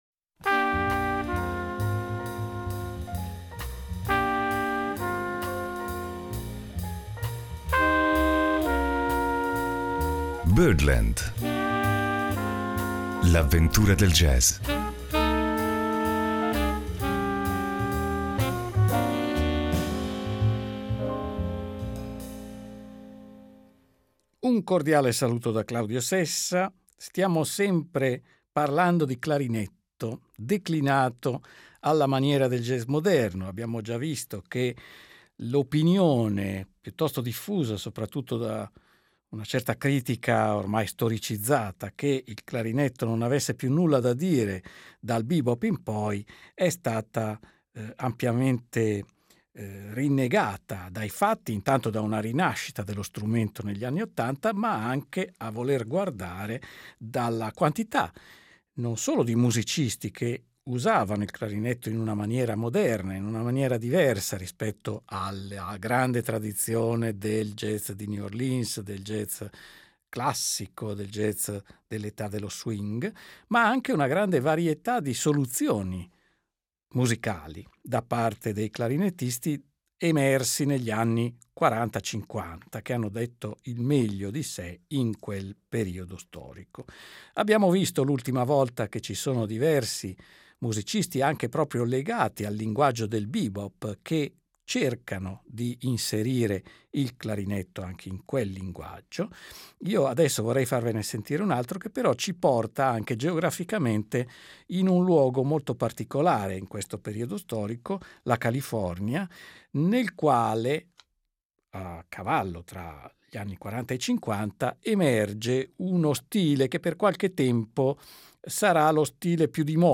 Il clarinetto nel jazz moderno (4./5)